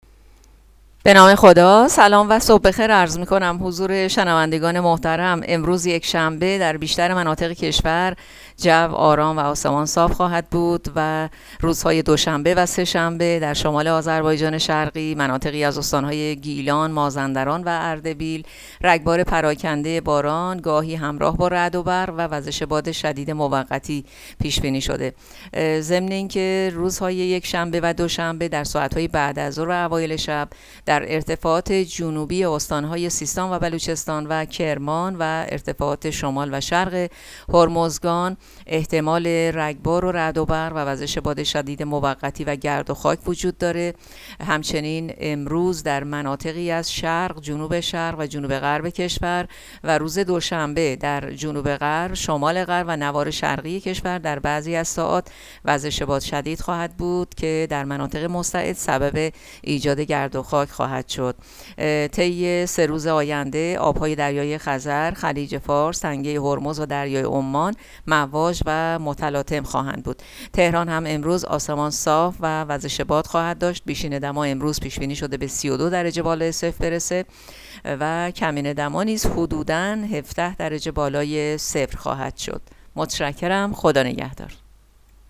گزارش رادیو اینترنتی پایگاه‌ خبری از آخرین وضعیت آب‌وهوای ۲۸ اردیبهشت؛